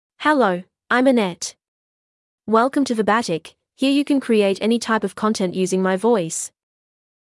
FemaleEnglish (Australia)
Annette — Female English AI voice
Annette is a female AI voice for English (Australia).
Voice sample
Annette delivers clear pronunciation with authentic Australia English intonation, making your content sound professionally produced.